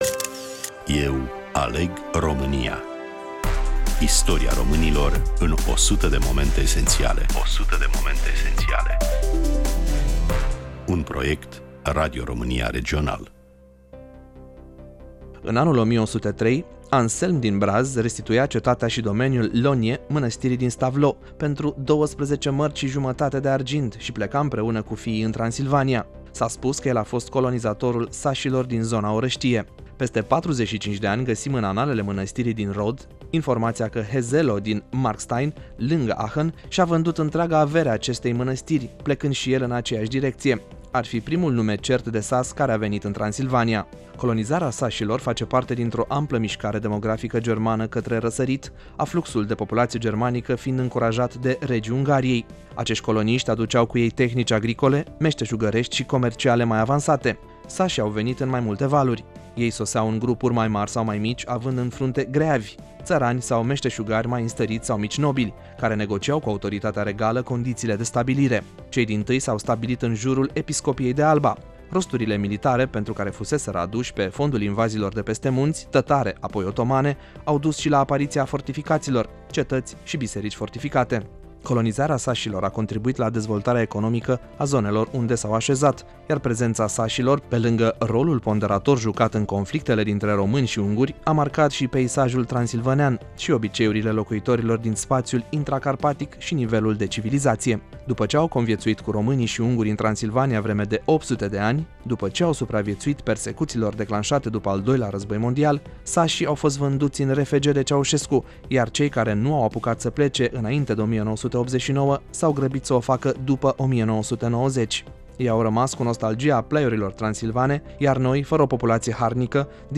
Coordonator proiect, voice over